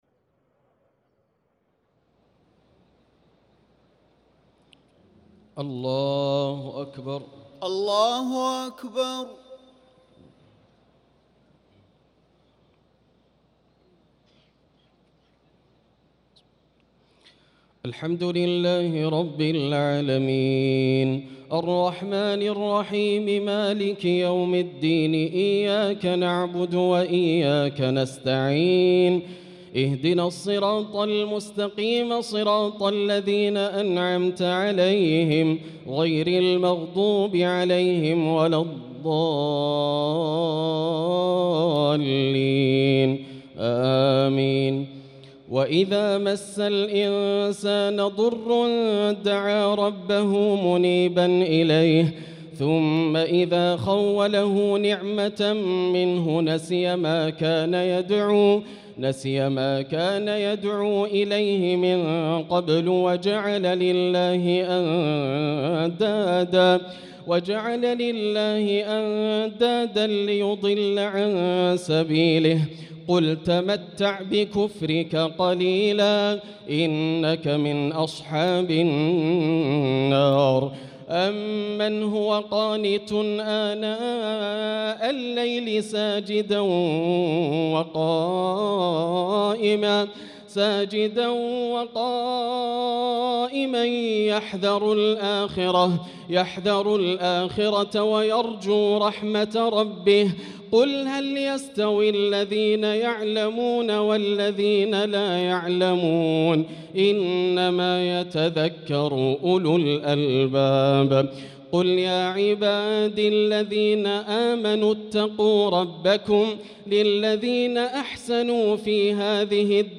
صلاة التراويح ليلة 25 رمضان 1445 للقارئ ياسر الدوسري - التسليمة الثالثة والرابعة صلاة التهجد